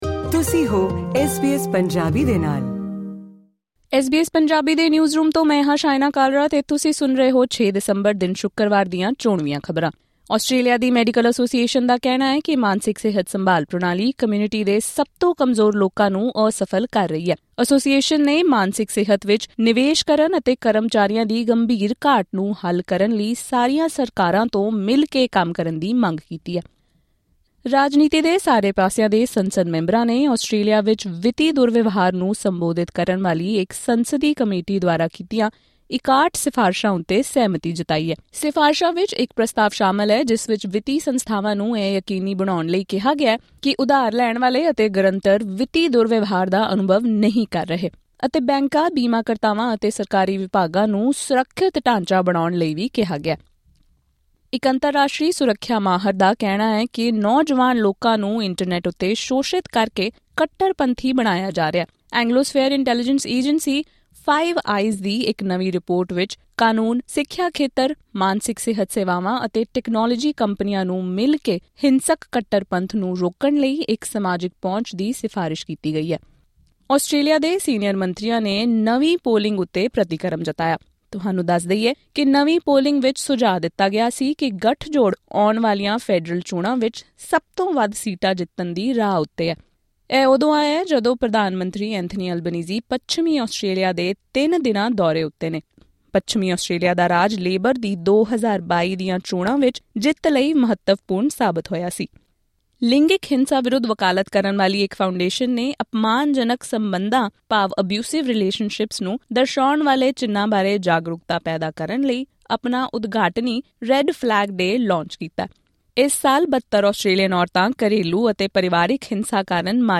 ਇਸ ਪੌਡਕਾਸਟ ਰਾਹੀਂ ਸੁਣੋ ਅੱਜ, ਦਸੰਬਰ 6 ਸ਼ੁਕਰਵਾਰ, ਦੀਆਂ ਮੁੱਖ ਖ਼ਬਰਾਂ